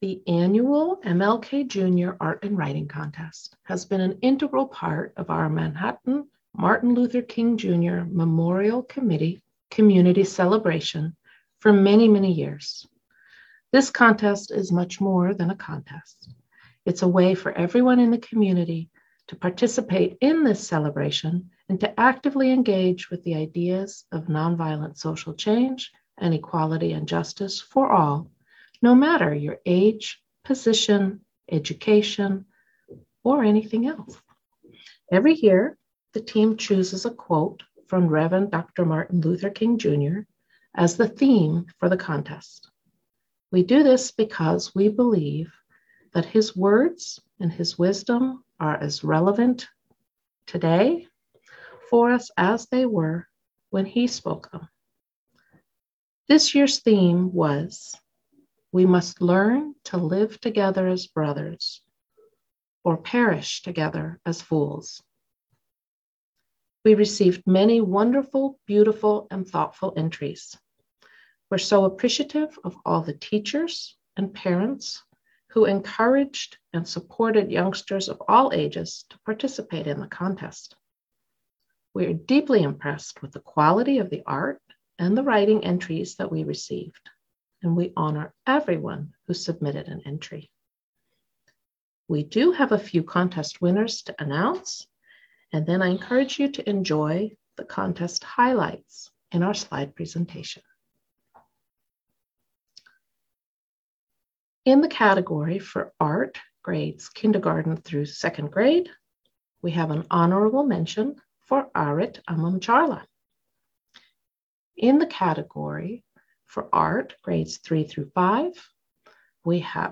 Highlights of the contest were presented in a slideshow at the virtual event and can be found hosted at the MLK Committee’s website by clicking here.